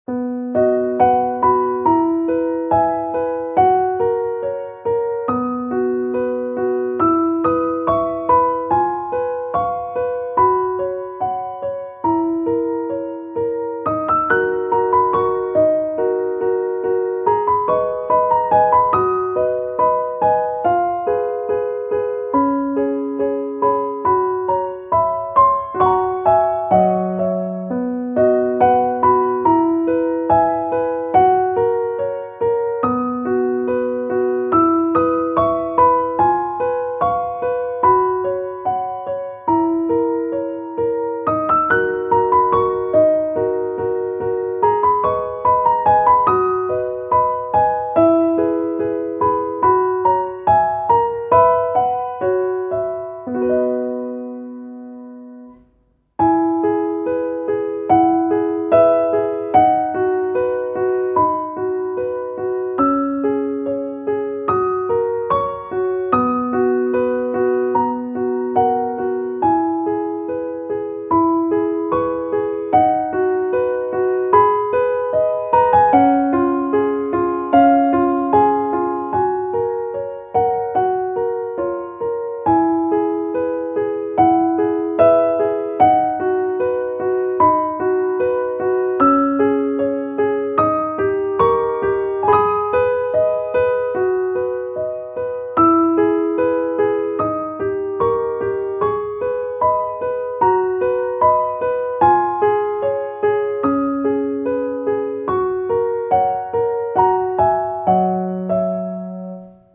-oggをループ化-   優雅 上品 2:22 mp3